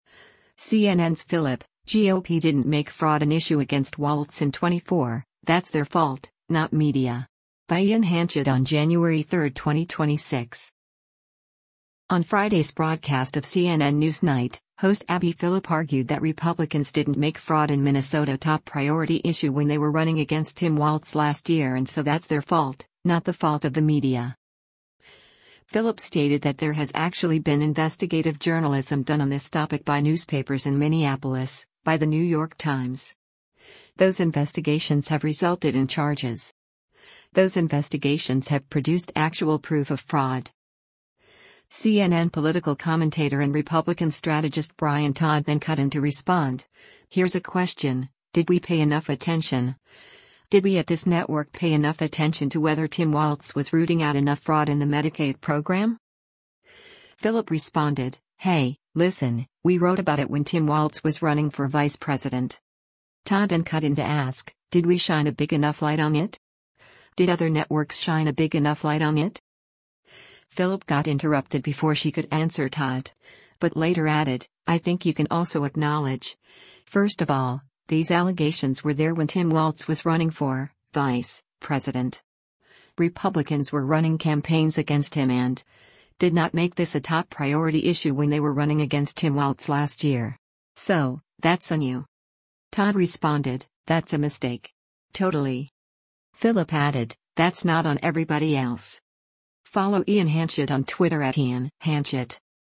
On Friday’s broadcast of “CNN NewsNight,” host Abby Phillip argued that Republicans didn’t make fraud in Minnesota “a top-priority issue when they were running against Tim Walz last year” and so that’s their fault, not the fault of the media.